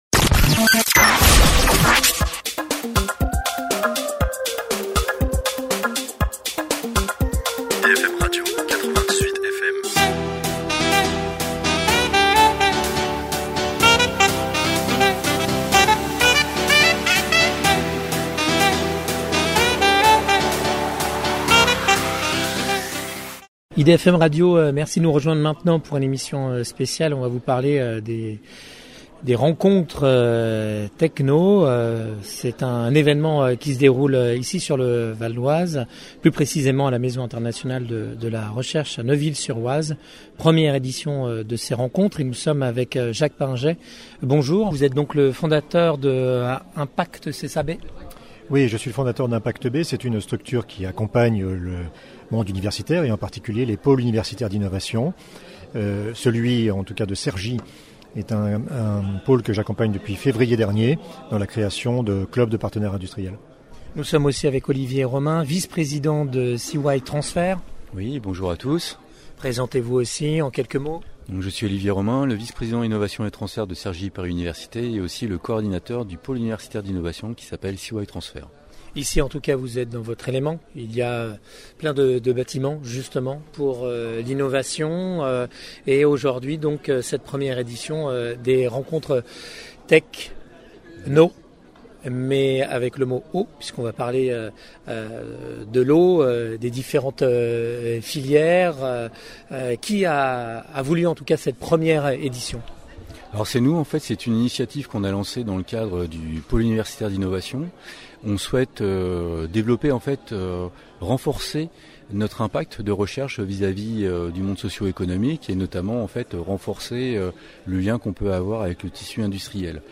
C’était Mercredi dernier le 19 Novembre au sein de la maison internationale de la recherche à NEUVILLE-SUR-OISE. La filière de l’eau et ses défis.